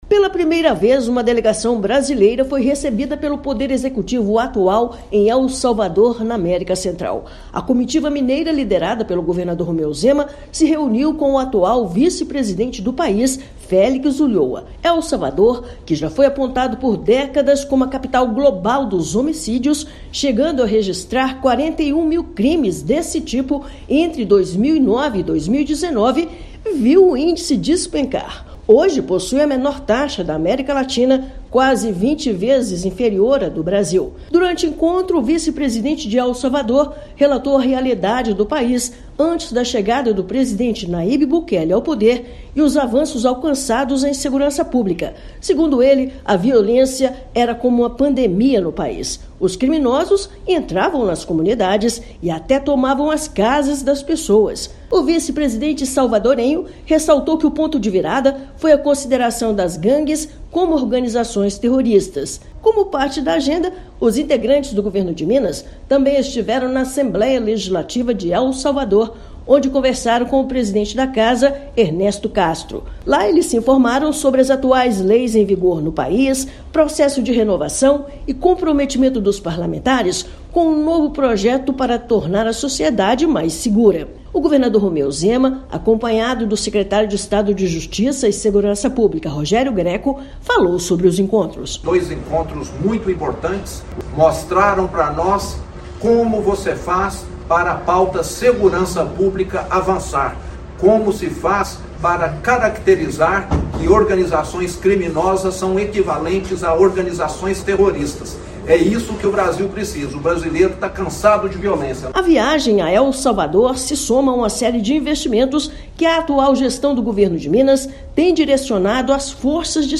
[RÁDIO] Governo de Minas se torna a primeira delegação brasileira recebida pelo atual Poder Executivo de El Salvador
Em missão oficial, governador e outros membros da comitiva se reuniram com o vice-presidente do país da América Central para conhecerem as etapas do bem-sucedido programa de Segurança Pública do país. Ouça matéria de rádio.